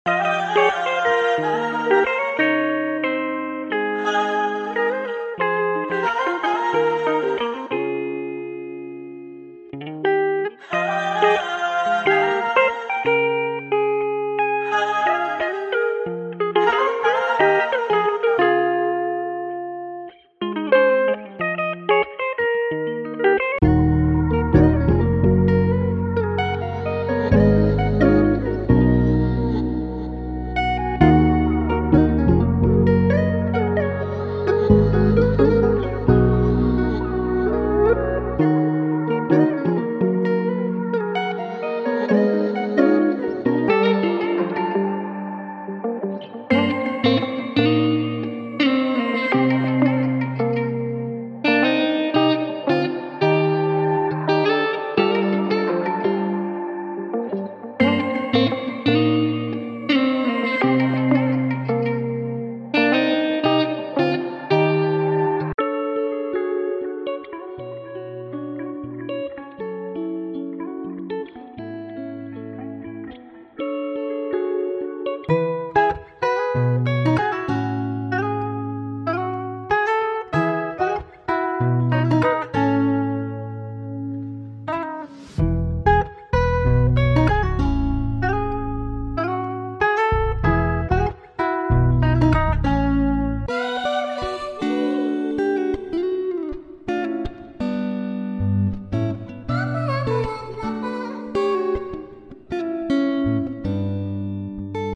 Hip Hop
Trap